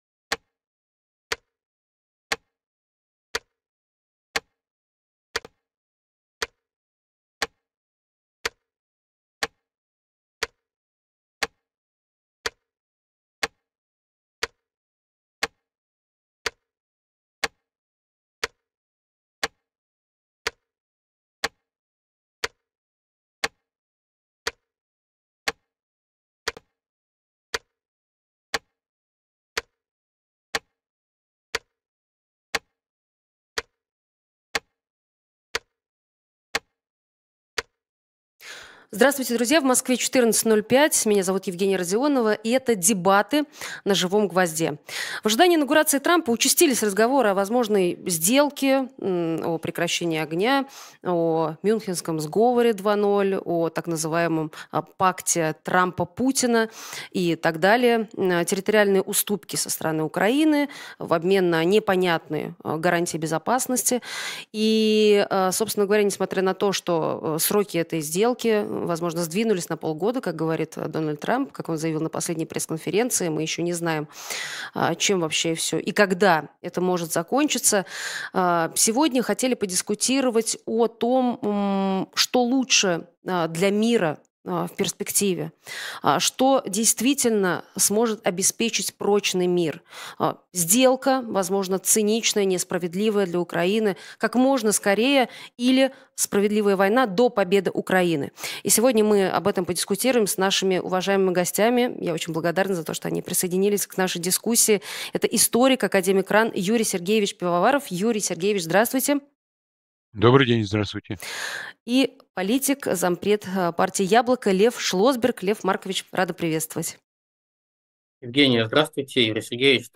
Дебаты Юрия Пивоварова и Льва Шлосберга: Похабный мир или справедливая война?